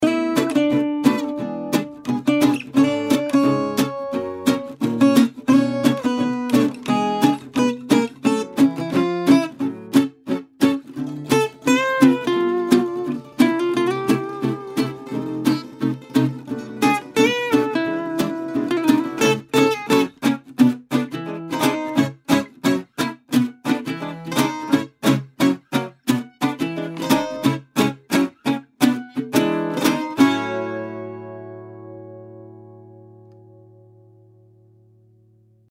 8 Guitar Ensemble Loops: Capture the authentic sound of a full Gypsy Jazz band with these rich, harmonic loops.
These loops range from upbeat, driving patterns to more laid-back grooves, offering versatility for any project.
6 Trill Loops: Add a touch of virtuosity with these expressive and dynamic trill loops.
6 Lead Guitar Riffs: Highlight your tracks with these captivating lead guitar riffs, expertly performed to embody the passionate spirit of Gypsy Jazz.
High-Quality Sound: Each loop is recorded and produced to the highest standards, ensuring pristine audio quality for your productions.
Gypsy-Jazz-Guitars-Vol-1.mp3